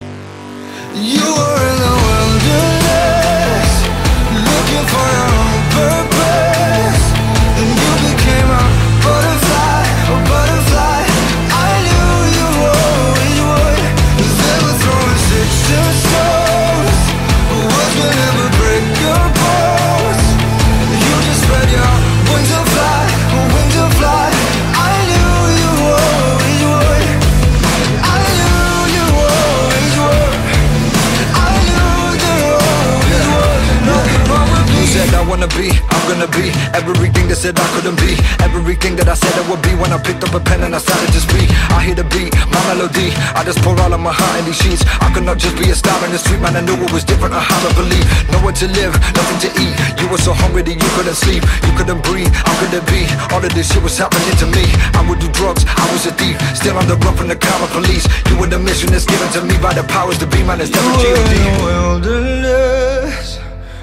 Pop ,Uncategorized